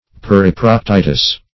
periproctitis - definition of periproctitis - synonyms, pronunciation, spelling from Free Dictionary
Search Result for " periproctitis" : The Collaborative International Dictionary of English v.0.48: Periproctitis \Per`i*proc*ti"tis\, n. [NL.